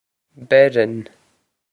Pronunciation for how to say
Ber-on
This is an approximate phonetic pronunciation of the phrase.